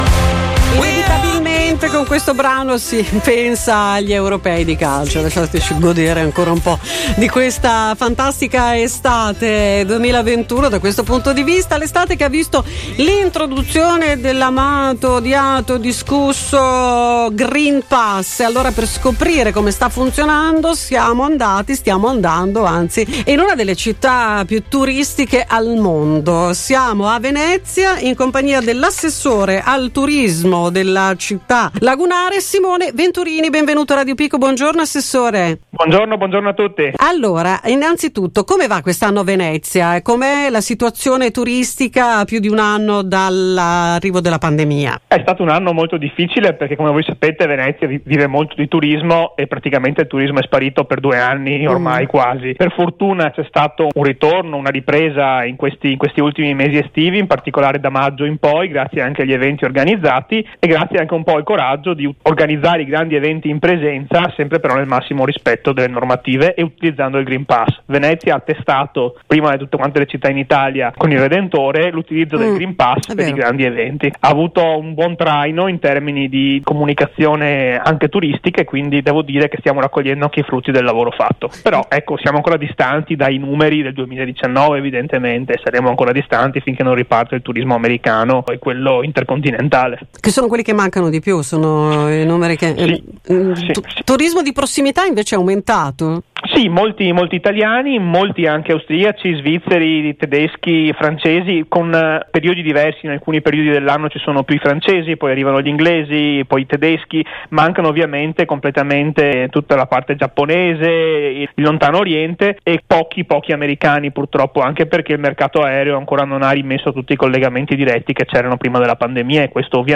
Venezia ha già superato la prova Green Pass, la nostra intervista all’Assessore al Turismo
assessore-venezia.mp3